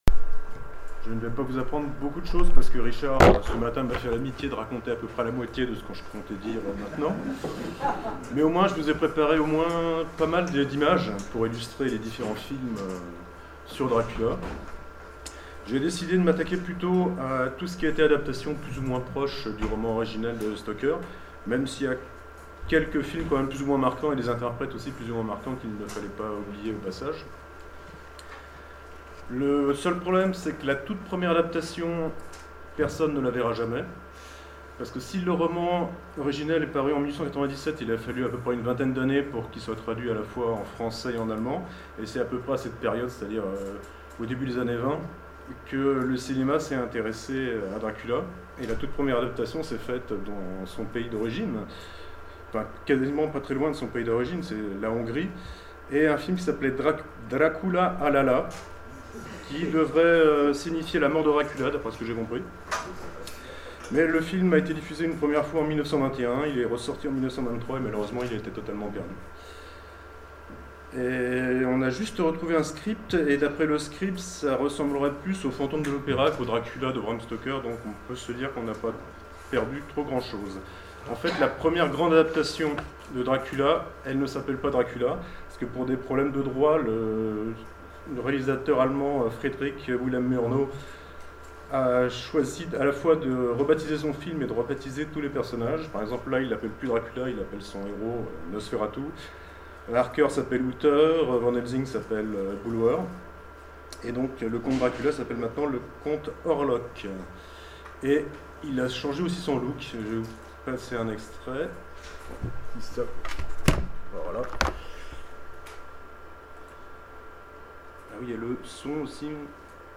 Conférence